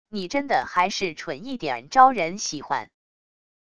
你真的还是蠢一点招人喜欢wav音频生成系统WAV Audio Player